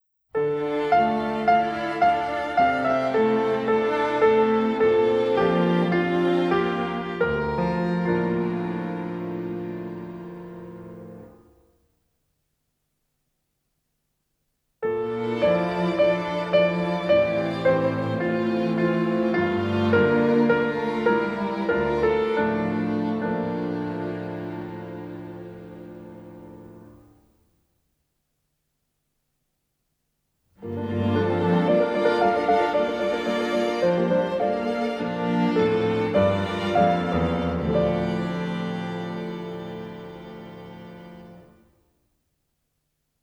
recorded at Abbey Road Studios